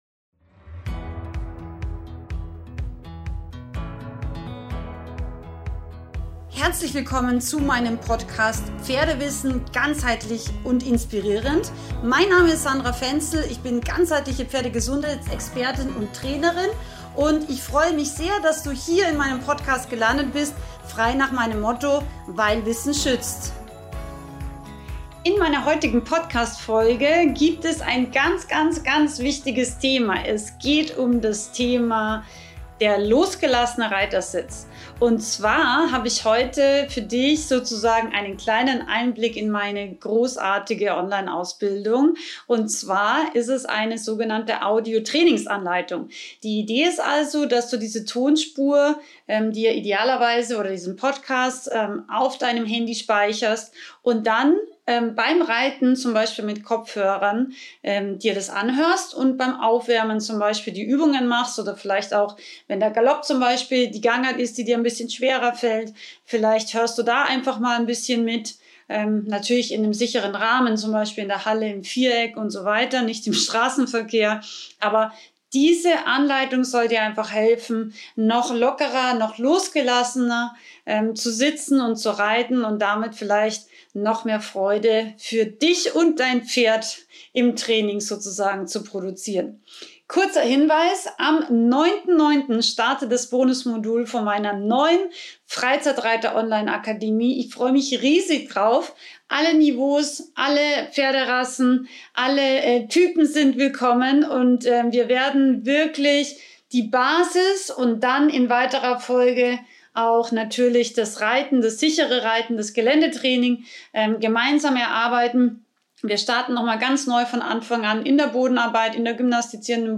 In dieser tollen Pferdepodcast-Episode erhältst Du eine Audio-Trainingsanleitung für einen losgelassenen Reitersitz & eine Webinareinladung!